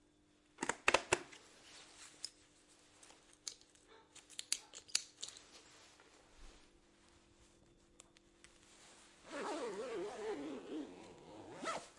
自行车 " 土路上的自行车轮胎
描述：在土路的自行车轮胎
标签： 道路 骑自行车 污垢 轮胎 自行车
声道立体声